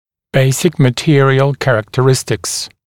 [‘beɪsɪk mə’tɪərɪəl ˌkærəktə’rɪstɪks][‘бэйсик мэ’тиэриэл ˌкэрэктэ’ристикс]основные характеристики материала, основные свойства материала